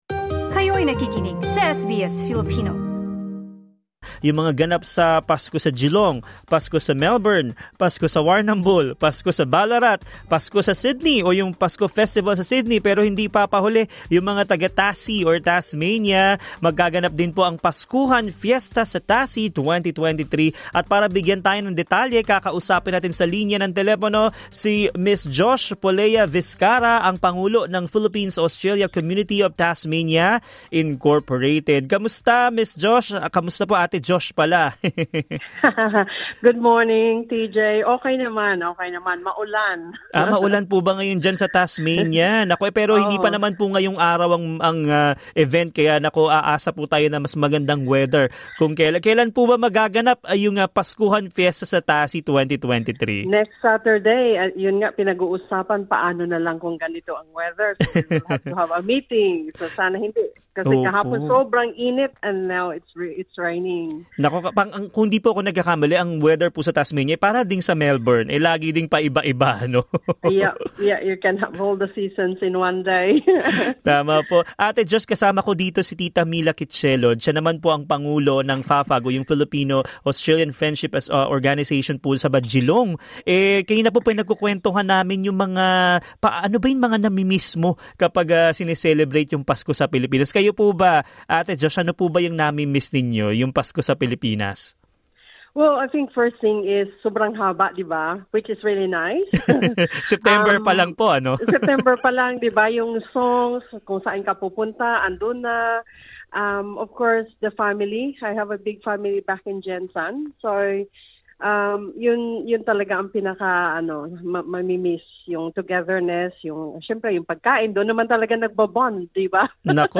Ang 'Paskuhan Fiesta in Tassie 2023' ay layong itaguyod ang mga tradisyon ng Pasko sa Filipino sa pamamagitan ng pagkain, kultural na mga pagtatanghal, laro, at raffle. Sa panayam ng SBS Filipino